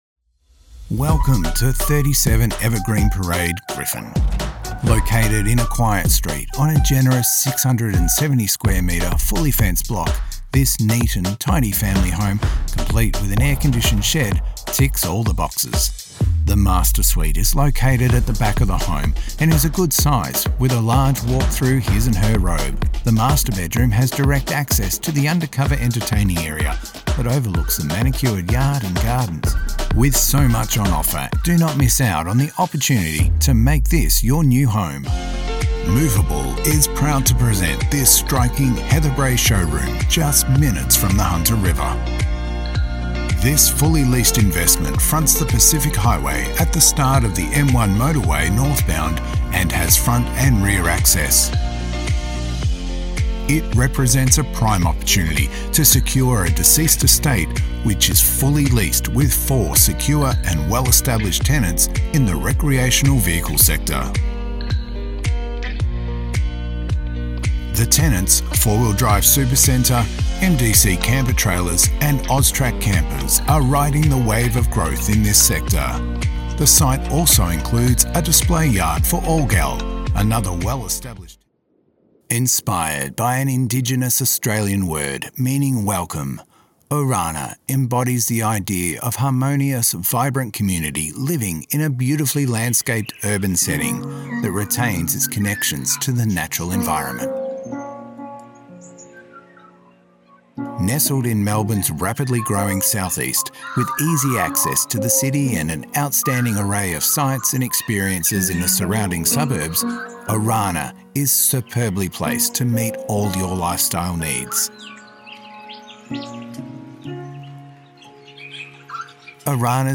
Real Estate Demo
English - Australian
Middle Aged